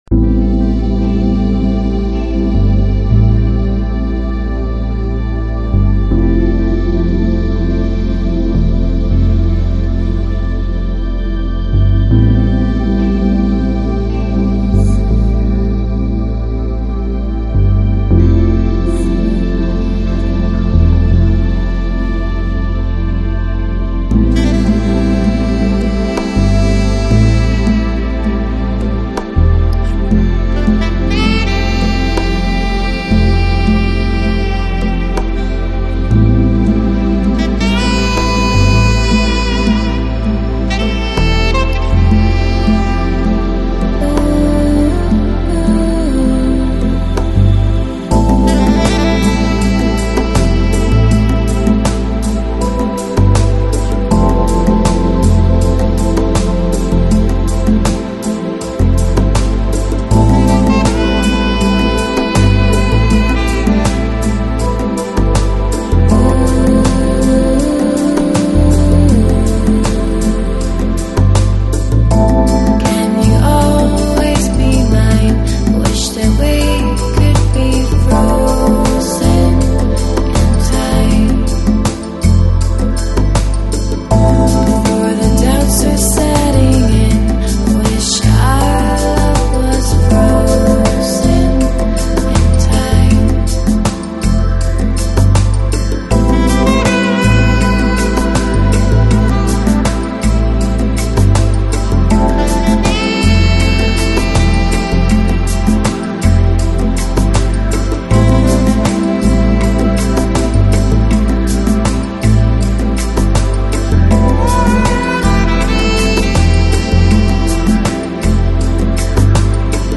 Electronic, Lounge, Chill Out, Downtempo